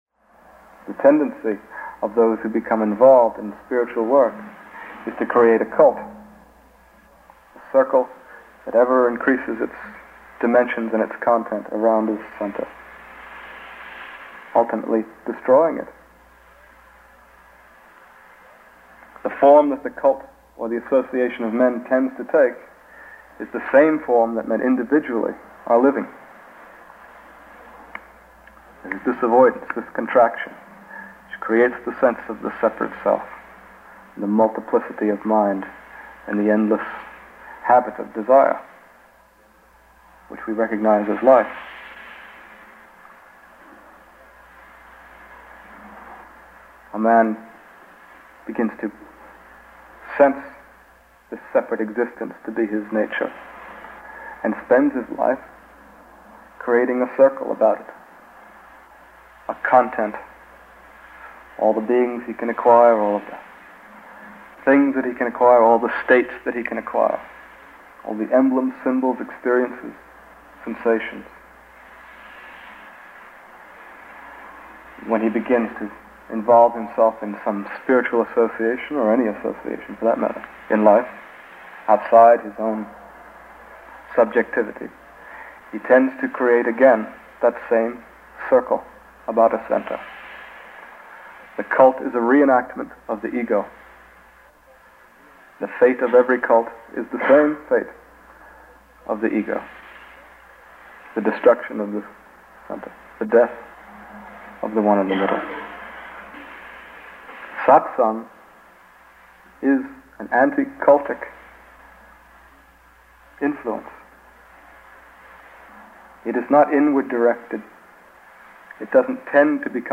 a humorous and penetrating Discourse